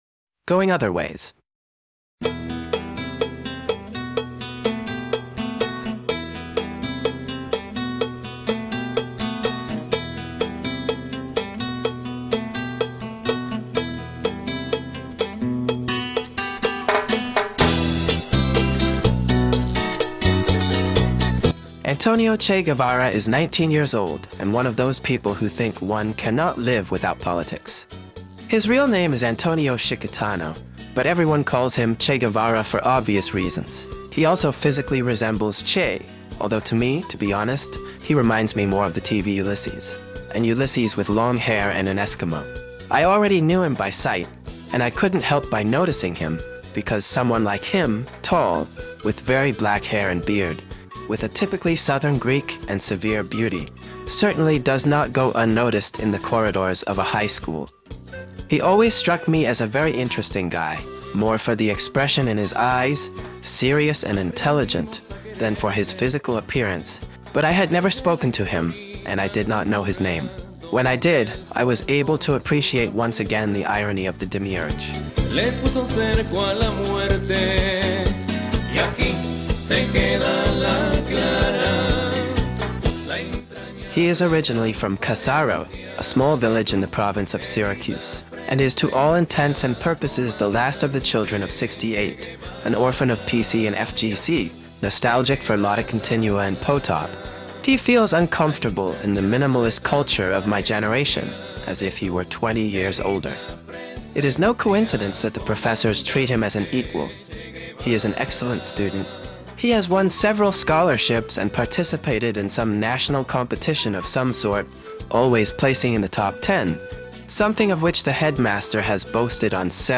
All interpreters are AI.